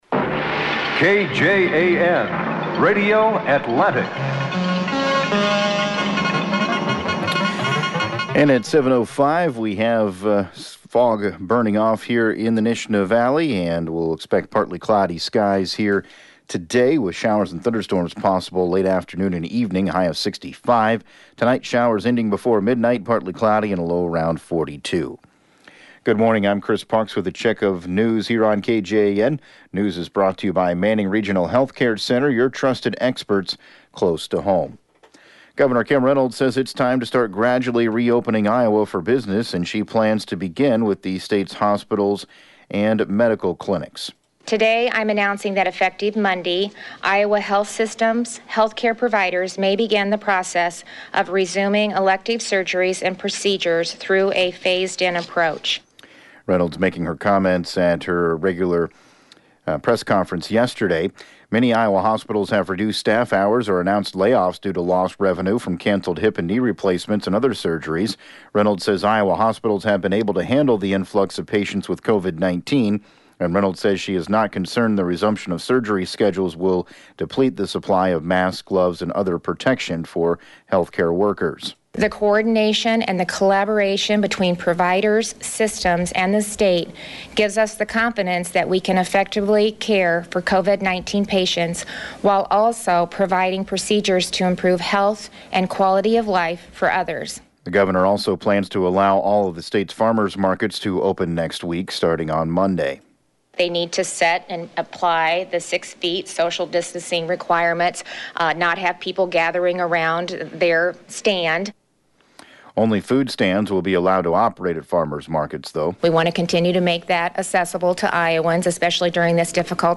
7AM Newscast 04/25/2020